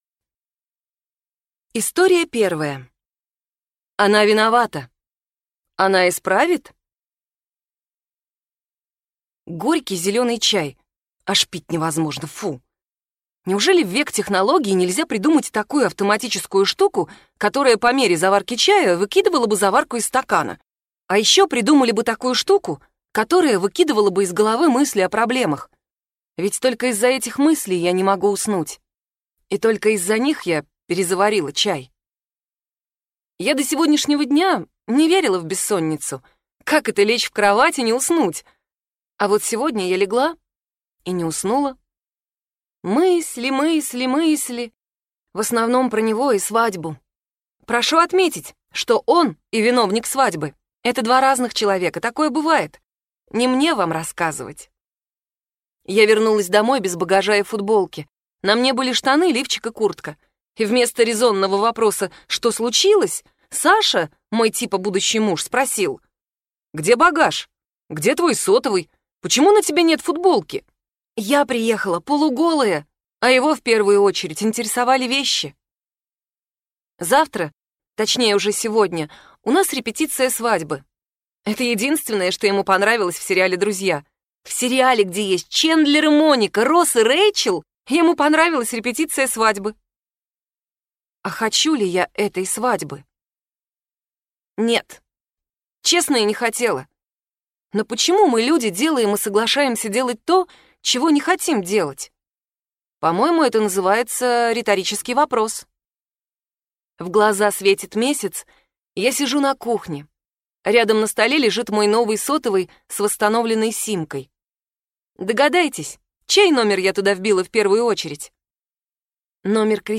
Аудиокнига Три истории | Библиотека аудиокниг